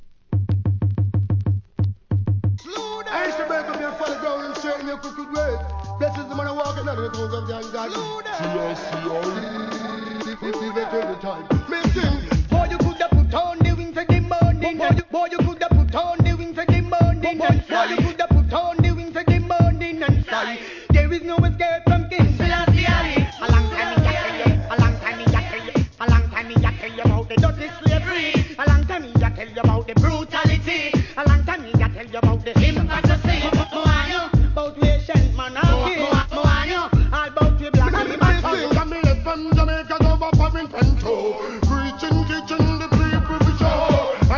HIP HOP X REGGAE MUSH UP物!!